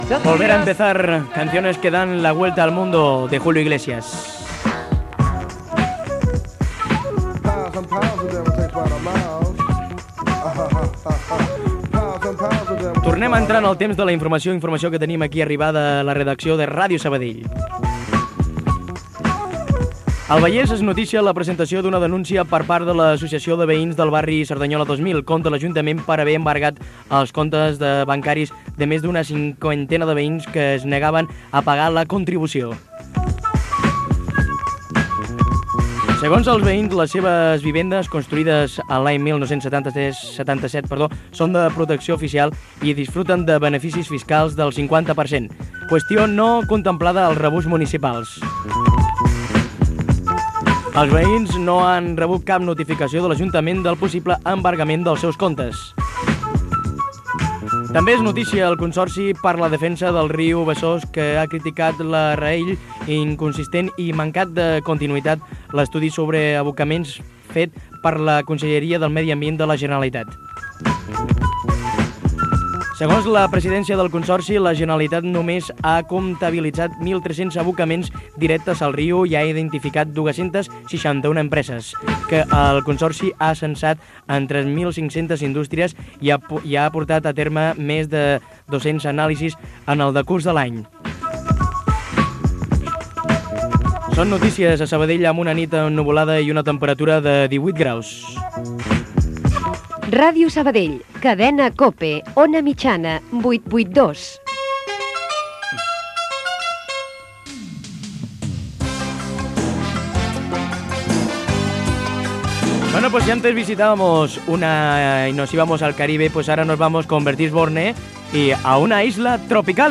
Tema musical. Notícies: barri Cerdanyola 2000, Consoci per la defensa del riu Besós . Indicatiu de l'emissora i de la cadena, presentació d'un tema musical.
Entreteniment